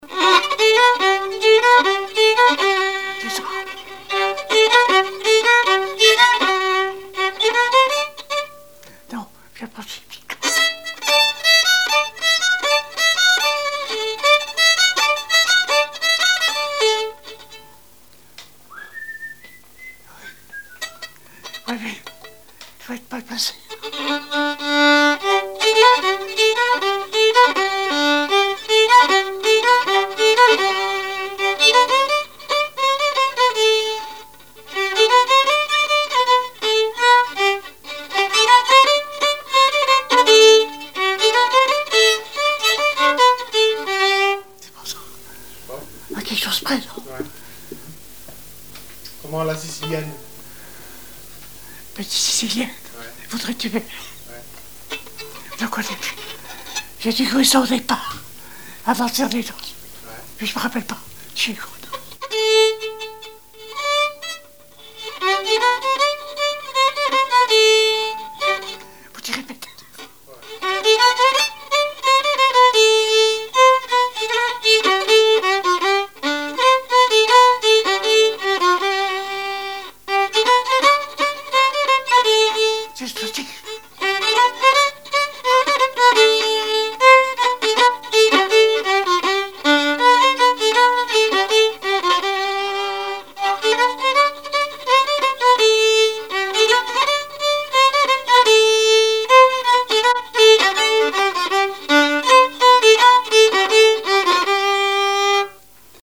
Mémoires et Patrimoines vivants - RaddO est une base de données d'archives iconographiques et sonores.
Chants brefs - A danser
Répertoire musical au violon
Pièce musicale inédite